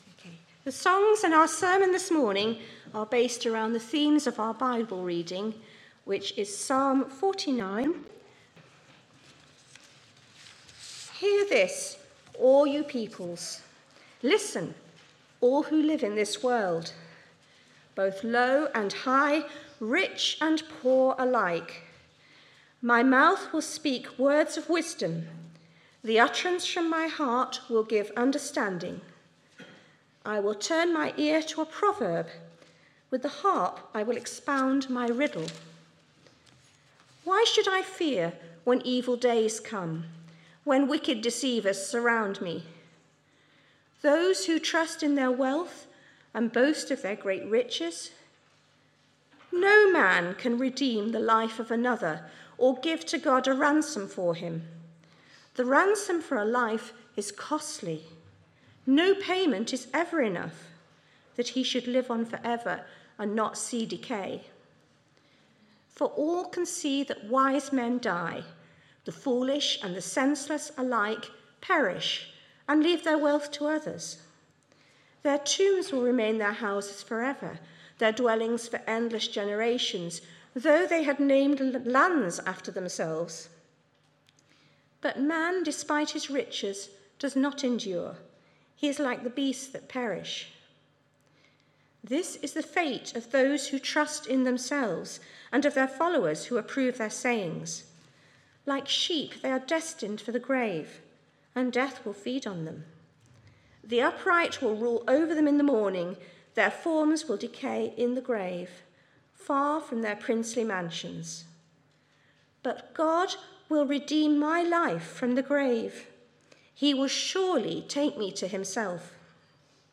Media for Sunday Service on Sun 17th Aug 2025 10:00
Passage: Psalm 49 Series: Summer of Psalms Theme: Sermon